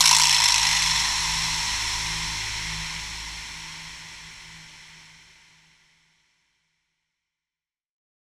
SOUTHSIDE_percussion_verbed_for_life.wav